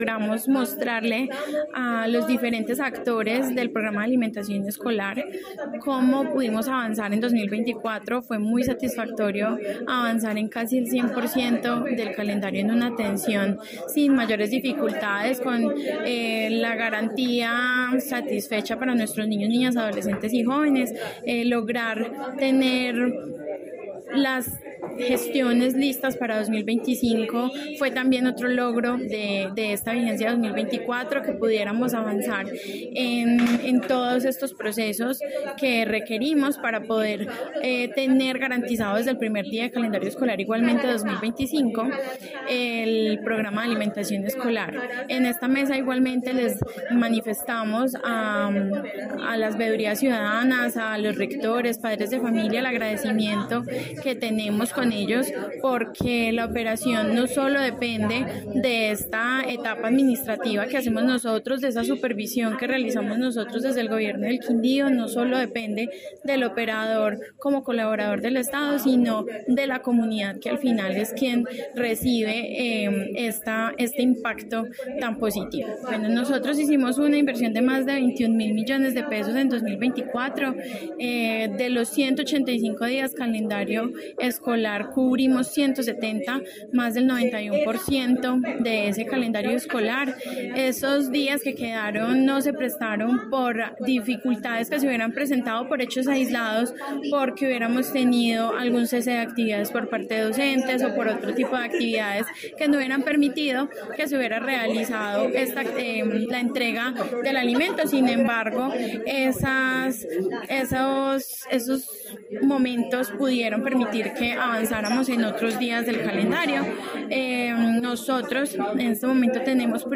Audio: Tatiana Hernández Mejía, secretaria de Educación departamental.
Tatiana-Hernandez-Mejia-secretaria-de-Educacion-departamental_Mesa-de-Participacion-Ciudadana-PAE-2024.mp3